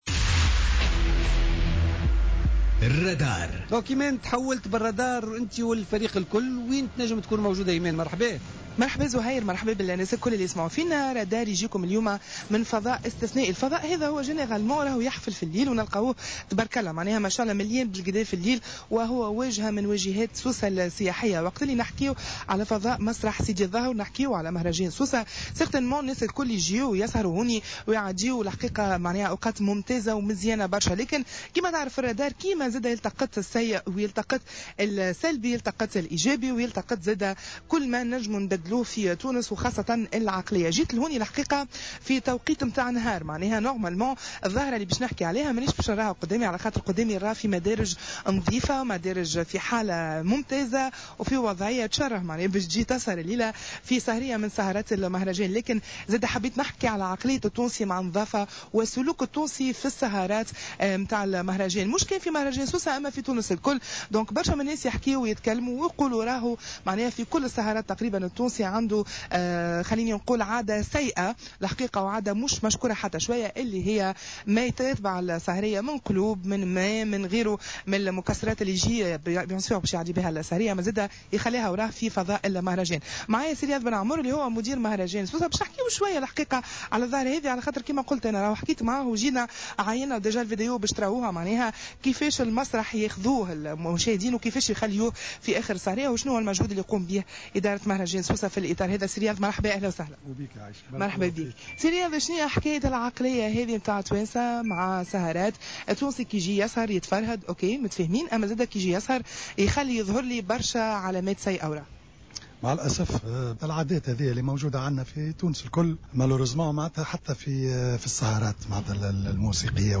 تحول فريق "الرادار" اليوم الاثنين 07 أوت 2017، إلى فضاء مسرح الهواء الطلق بسيدي ظاهر، لمعاينة وضعيته بعد السهرات الفنية.